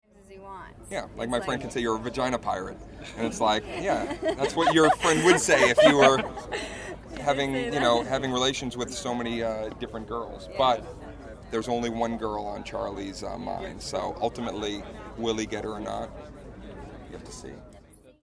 It turned into a 3 ½ minute interview done just before they jumped into the press line. So, call it an extended press-line interview or a warm-up, if you will.